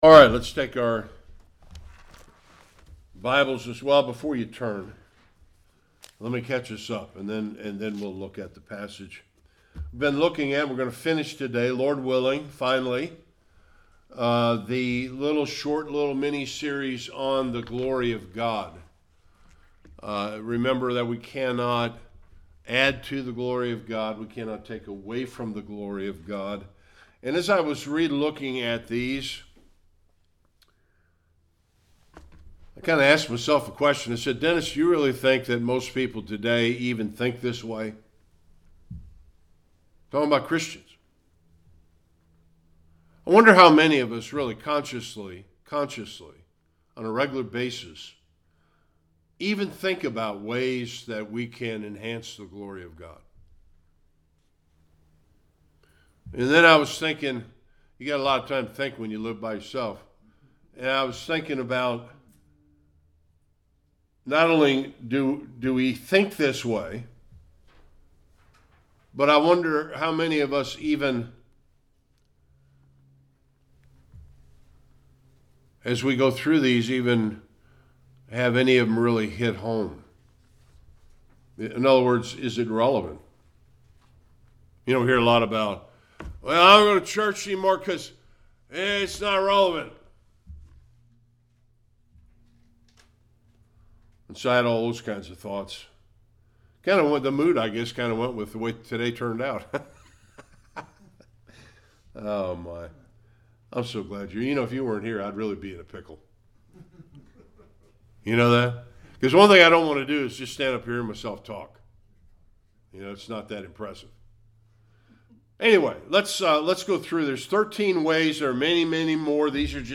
Various Passages Service Type: Sunday Worship The conclusion of 13 ways we can enhance the glory of God.